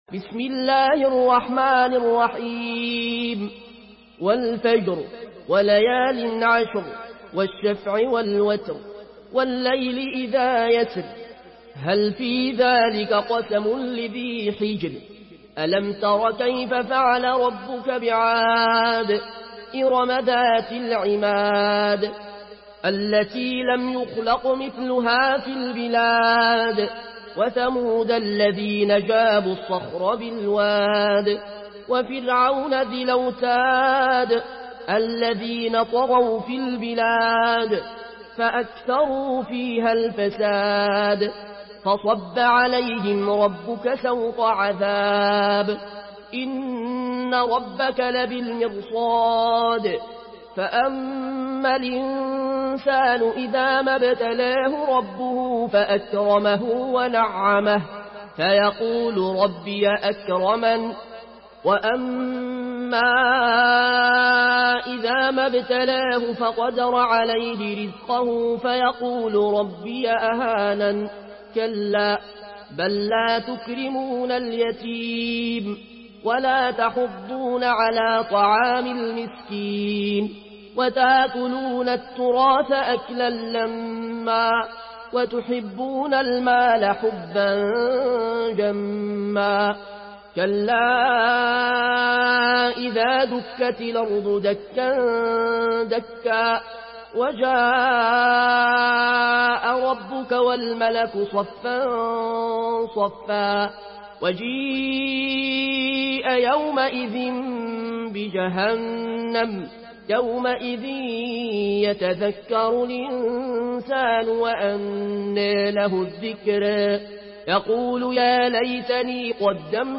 Une récitation touchante et belle des versets coraniques par la narration Warsh An Nafi From Al-Azraq way.